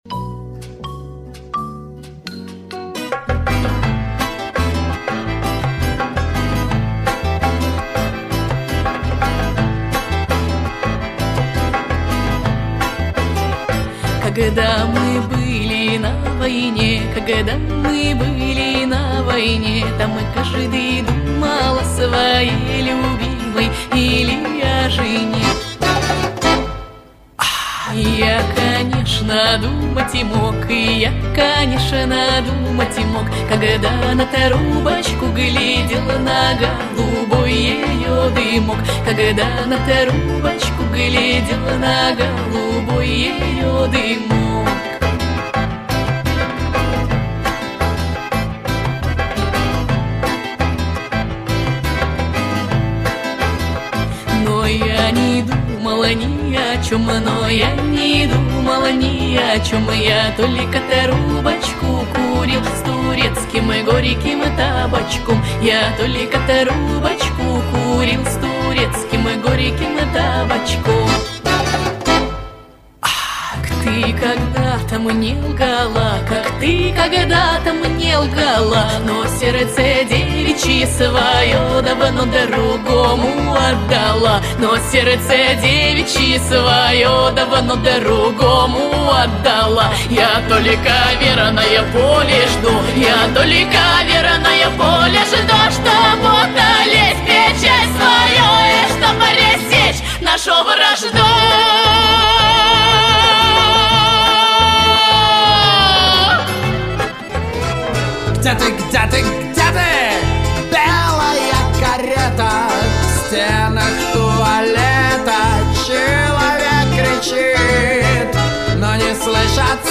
Авторское исполнение (на стих Д. Самойлова "Песенка гусара")